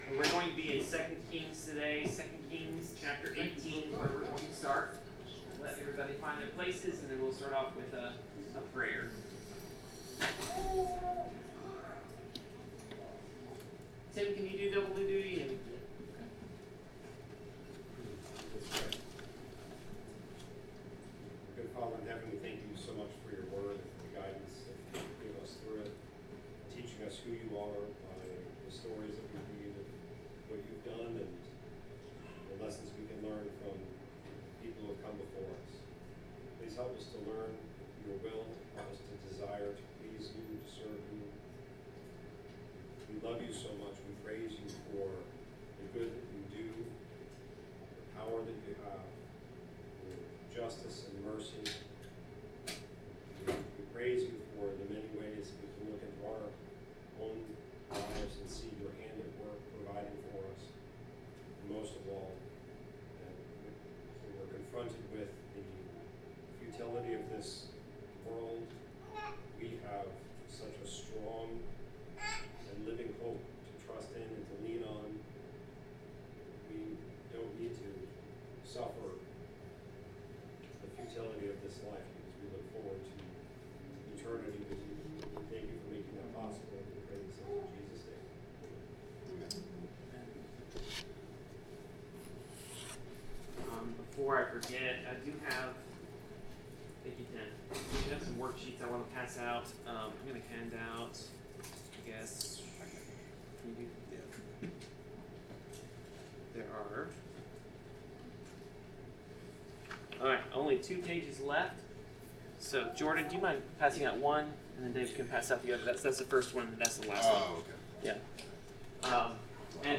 Bible class: 2 Kings 18-19
Passage: 2 Kings 18-19 Service Type: Bible Class